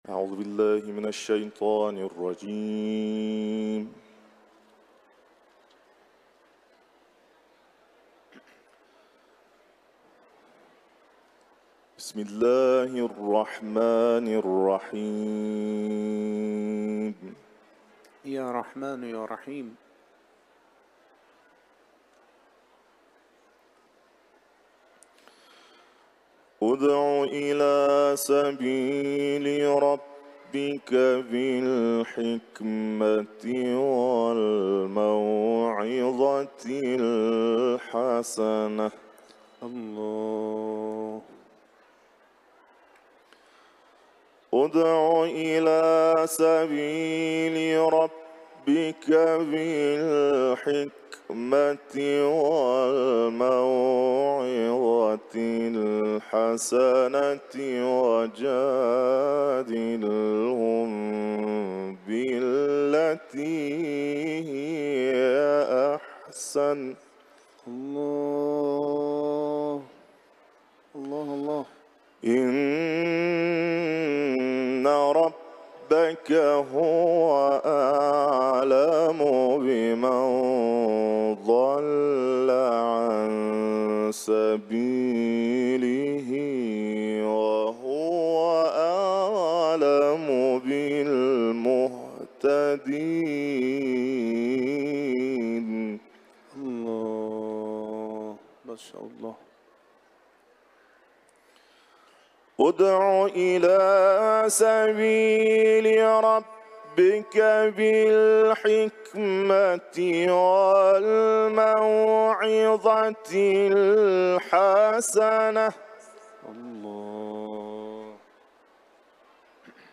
İranlı kârinin Kur’an-ı Kerim’den ayetleri tilaveti
Etiketler: İranlı kâri ، Kuran tilaveti ، Nahl suresi